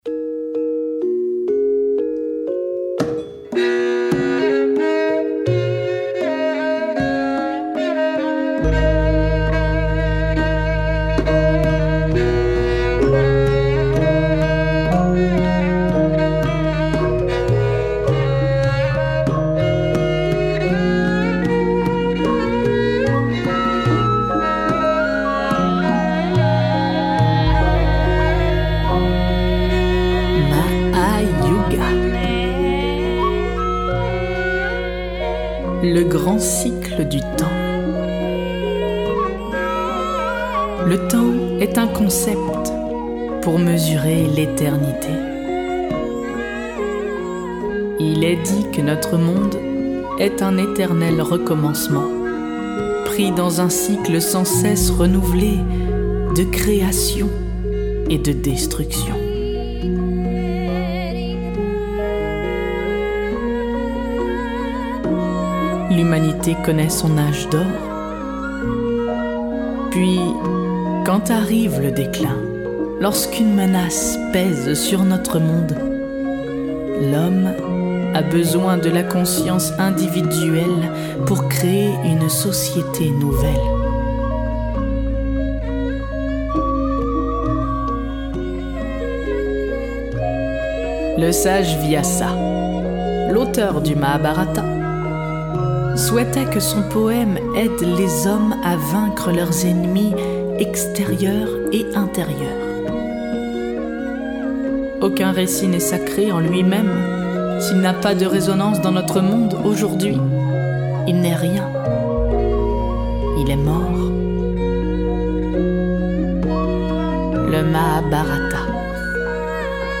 Contes d’auteurs
accompagnée d’un gamelan